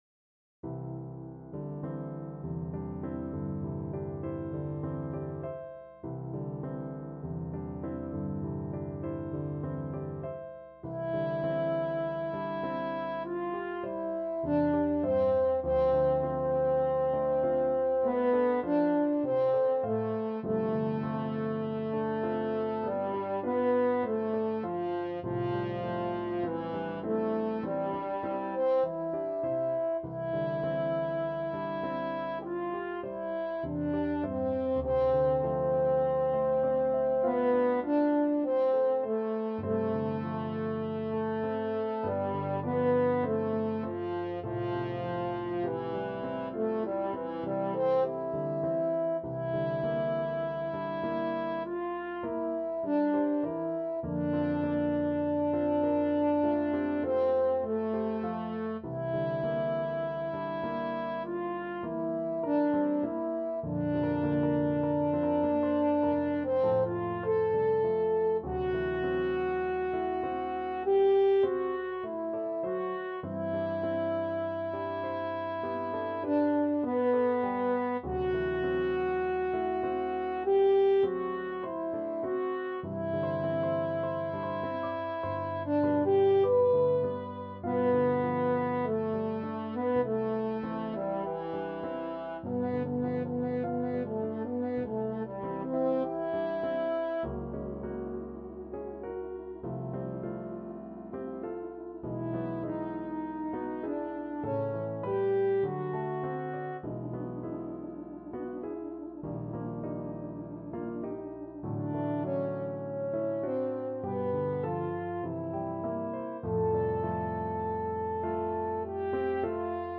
French Horn
C major (Sounding Pitch) G major (French Horn in F) (View more C major Music for French Horn )
4/4 (View more 4/4 Music)
Lento =50
Classical (View more Classical French Horn Music)